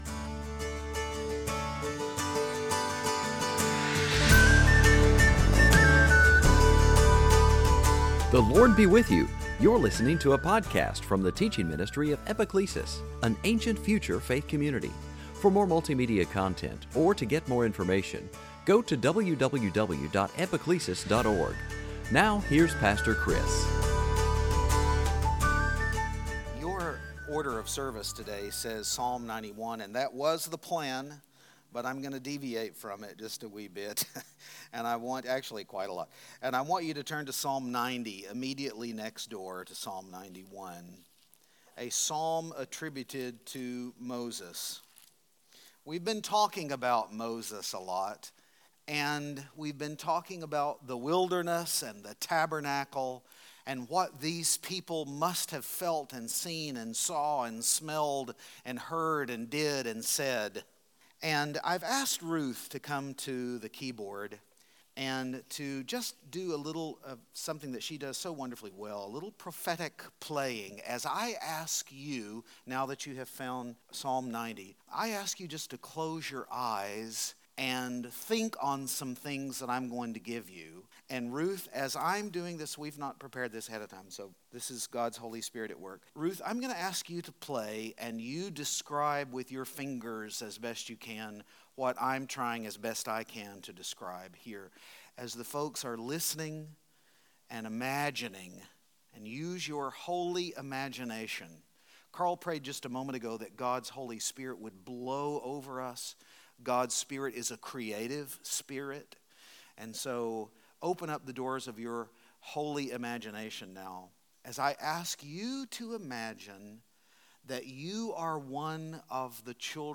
2019 Sunday Teaching Eden eschaton Garden heaven Moses revelation Tabernacle Season after Pentecost